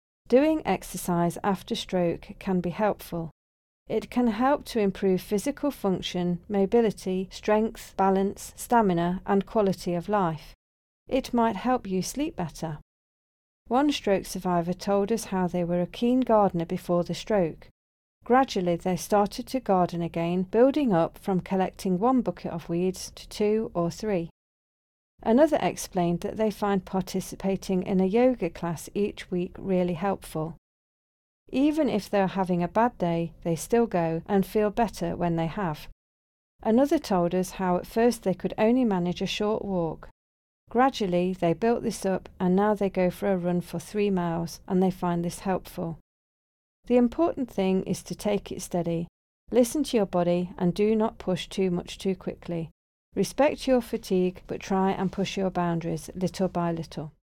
Listen to section 7 narration audio (MP3)